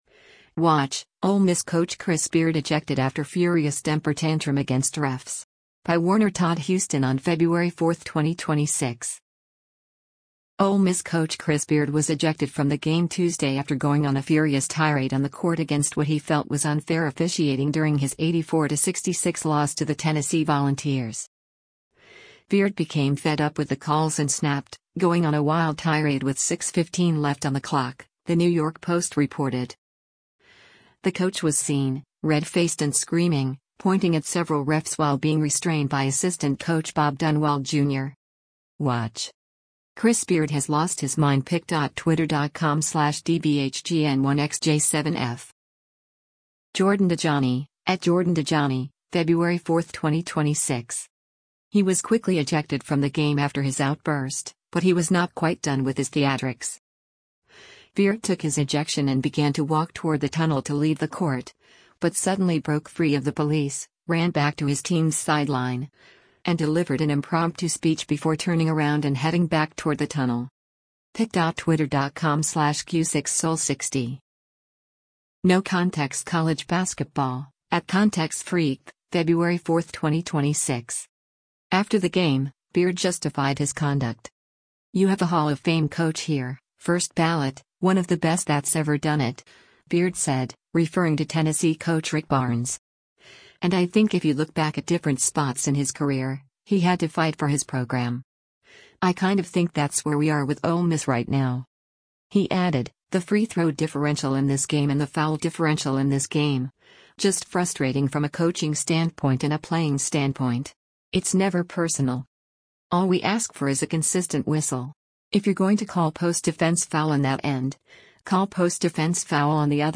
Ole Miss coach Chris Beard was ejected from the game Tuesday after going on a furious tirade on the court against what he felt was unfair officiating during his 84-66 loss to the Tennessee Volunteers.